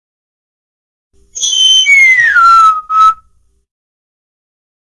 Качественный звук свиста